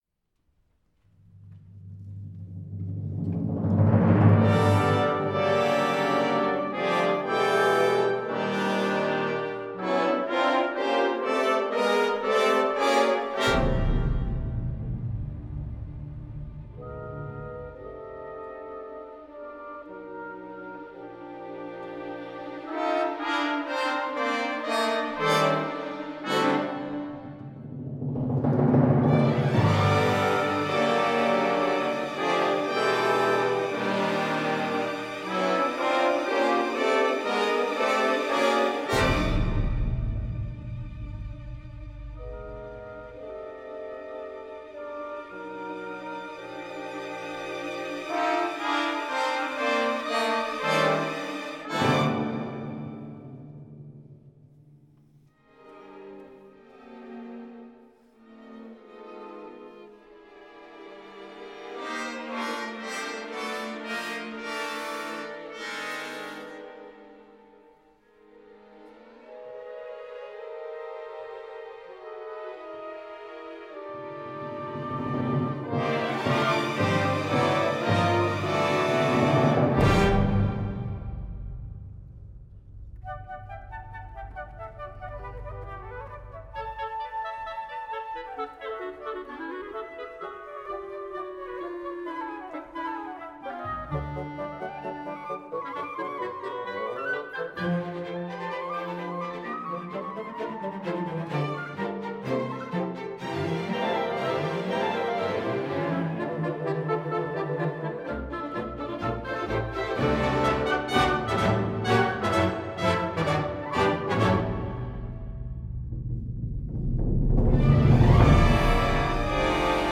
stage musical